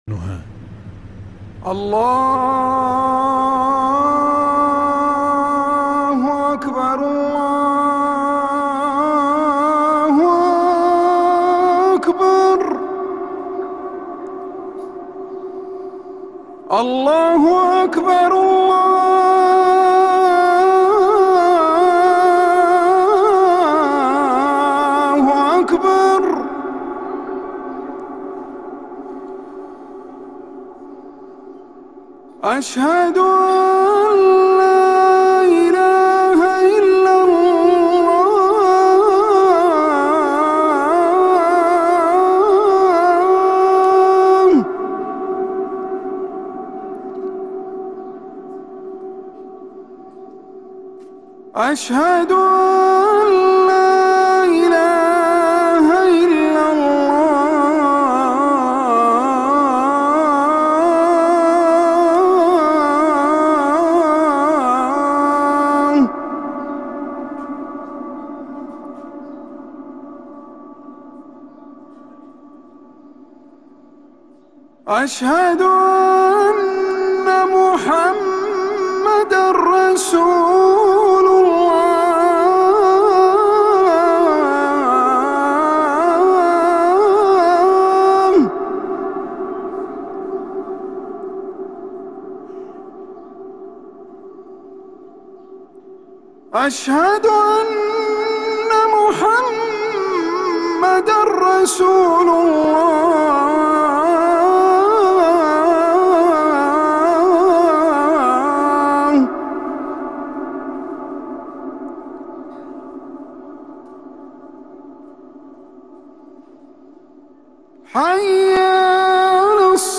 > روائع الأذان > ركن الأذان 🕌 > المزيد - تلاوات الحرمين